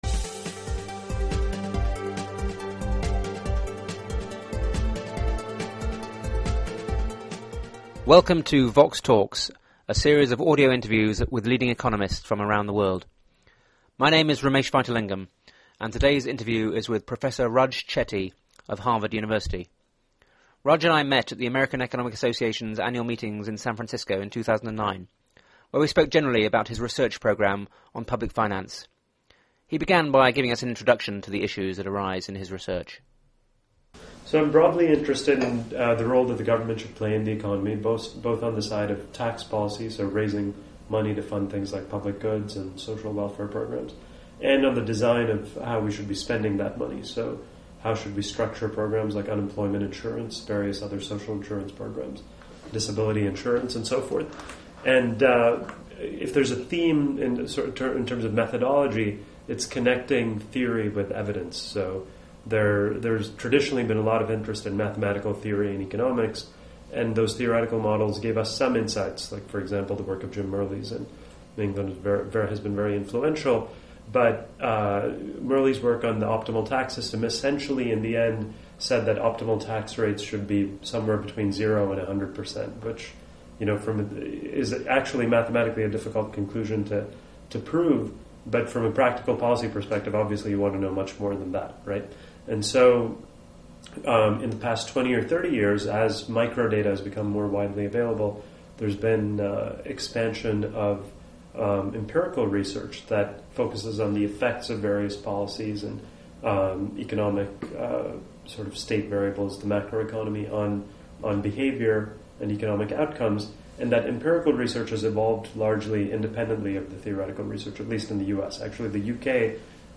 Raj Chetty interviewed
They discuss his work on US public finance as well as new research on how to improve tax compliance in developing countries. The interview was recorded at the American Economic Association meetings in San Francisco in January 2009.